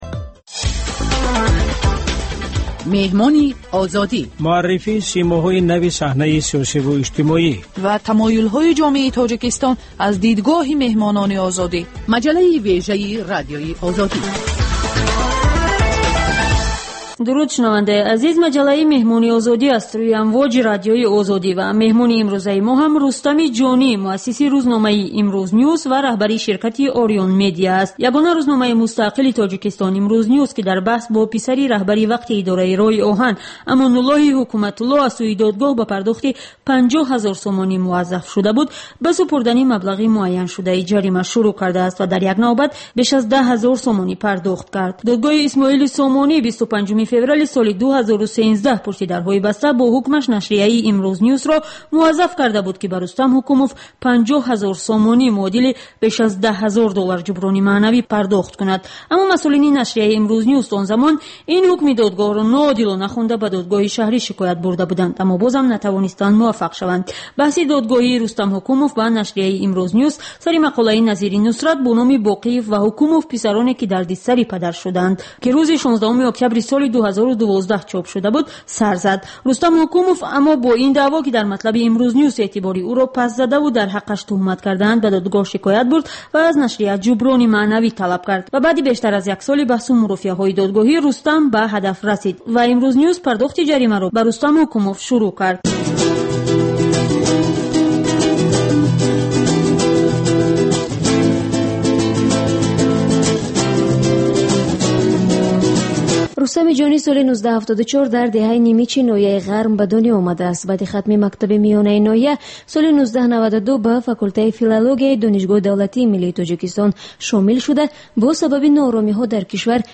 Гуфтугӯи ошкоро бо шахсони саршинос ва мӯътабари Тоҷикистон.